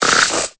Cri de Bargantua dans Pokémon Épée et Bouclier.